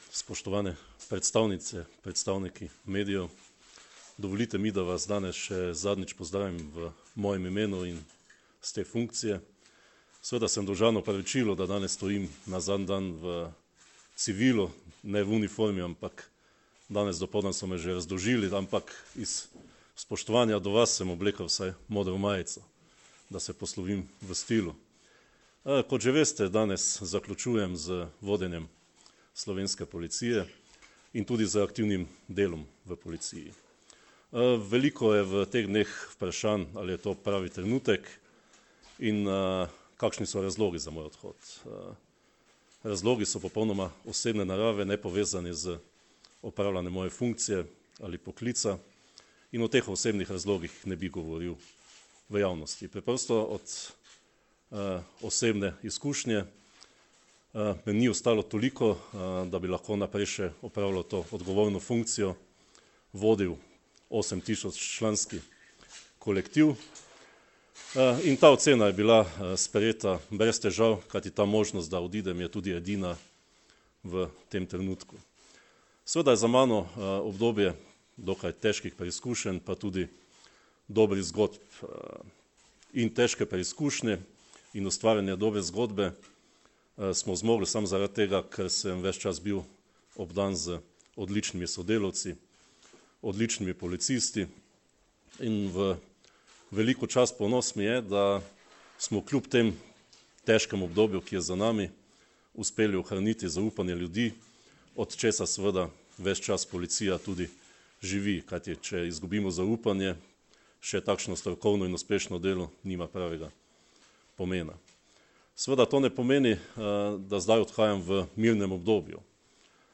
Zvočni posnetek izjave Marjana Fanka (mp3)